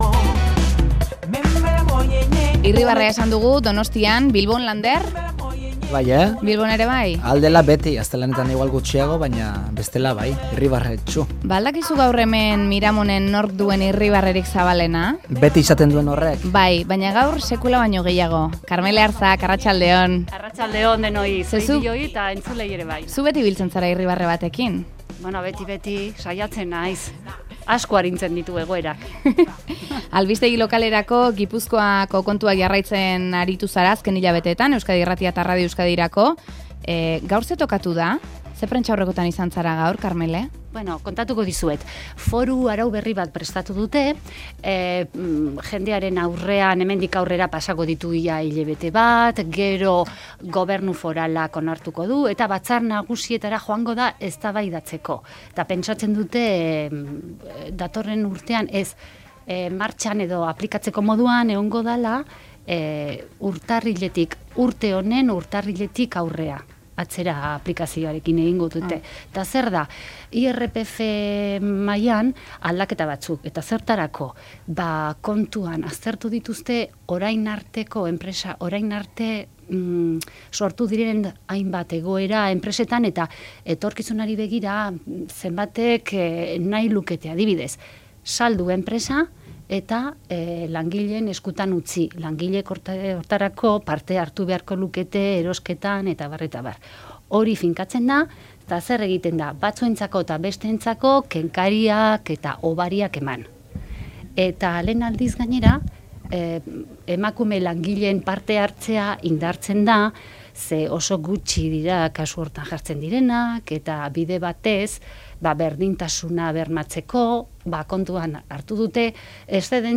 Ezagun egingo zaizkizue bere ahots goxoa eta irrifarrea.